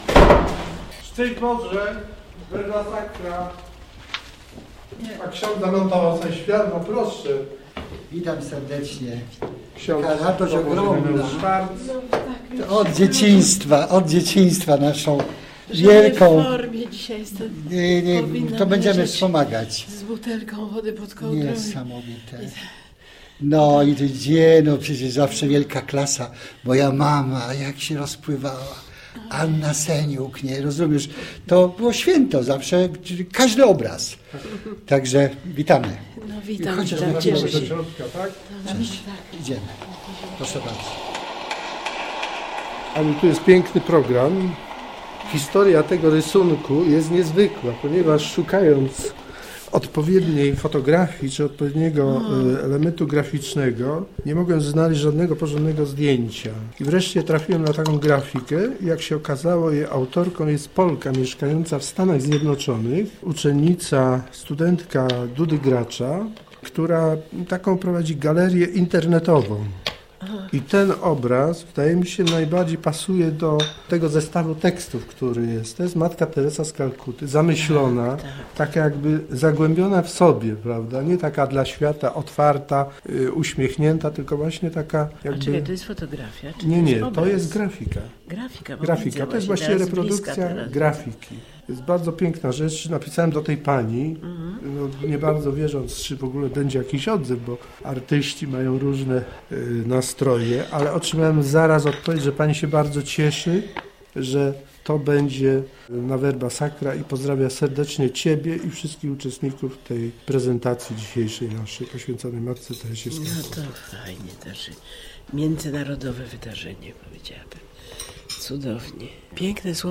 Szczęść Boże Verba Sacra - reportaż
Verba Sacra 2016. Teksty Matki Teresy w katedrze poznańskiej przeczytała Anna Seniuk.